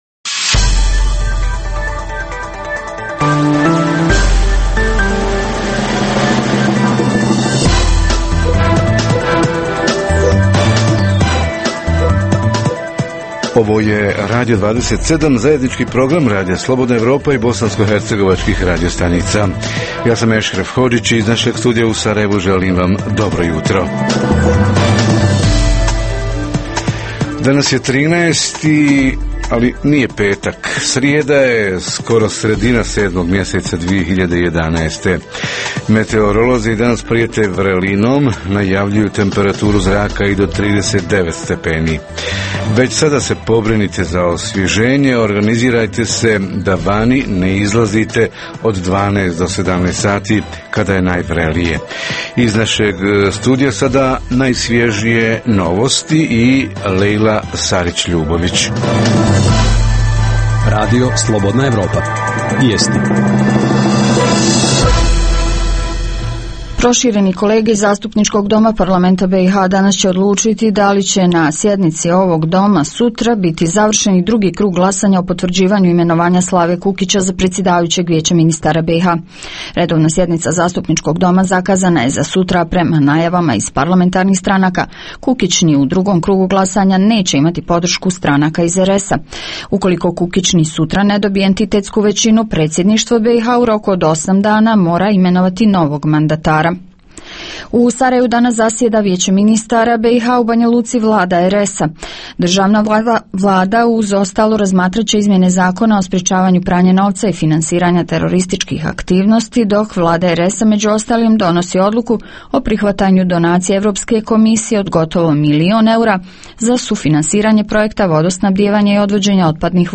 Općinske službe i javne ustanove - i godišnji odmori – ima li dugih redova, šta i kako da građani ne trpe? Reporteri iz cijele BiH javljaju o najaktuelnijim događajima u njihovim sredinama.